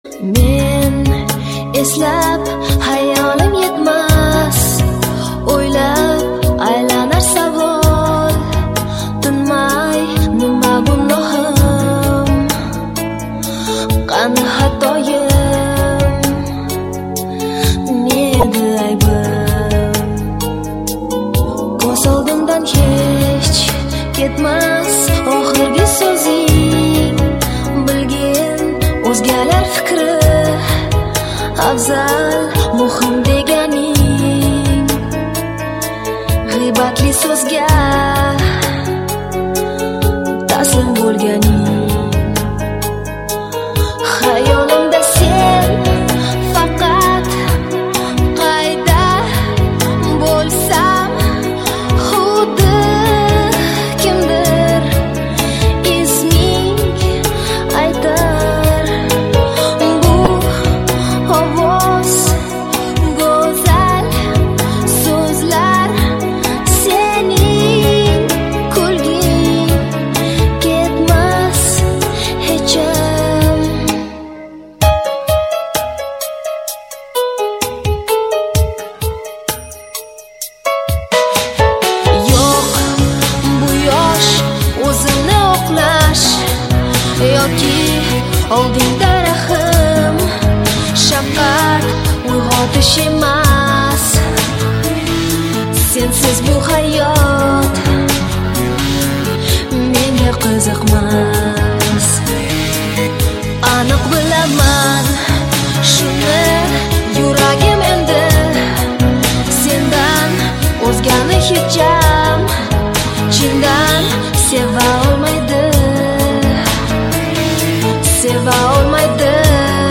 что-то_восточное_-_дагестанское_кажись
chto_to_vostochnoe___dagestanskoe_kazhisjq.mp3